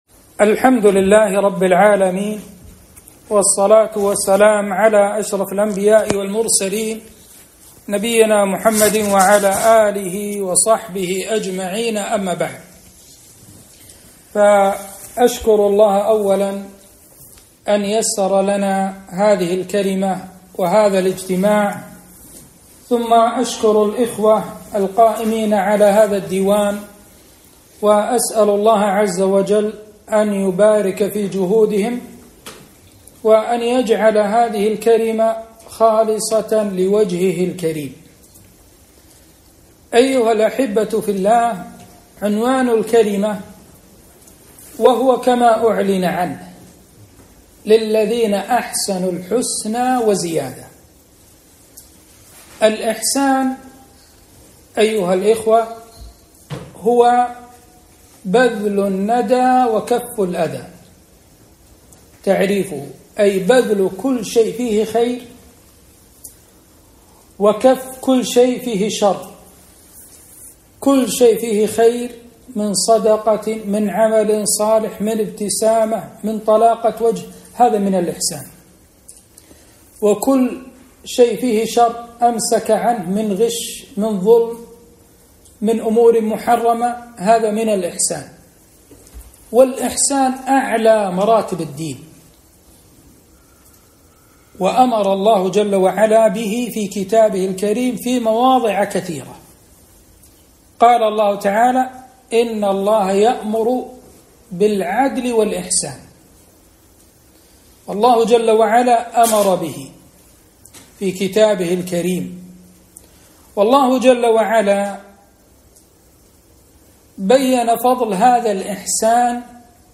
محاضرة - (للذين أحسنوا الحسنى وزيادة)